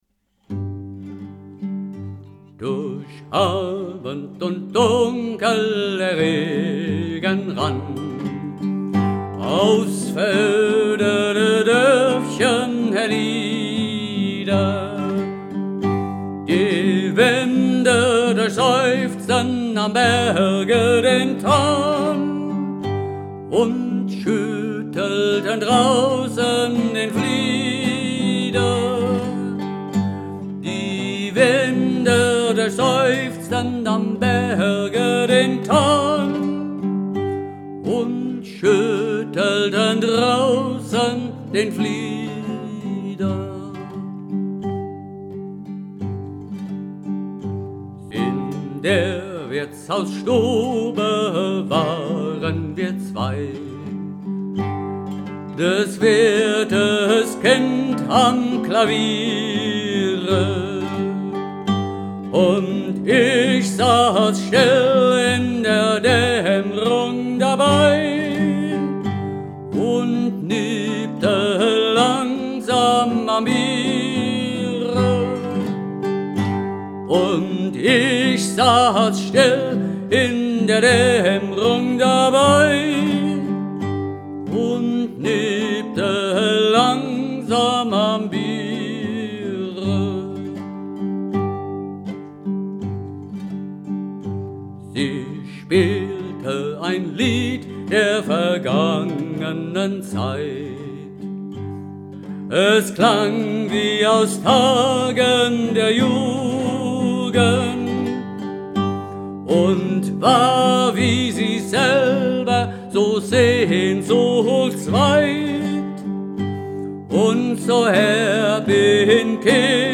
Die Hörprobe stammt von einer Studioaufnahme aus dem Jahr 2012.